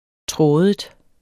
Udtale [ ˈtʁɔːðəd ]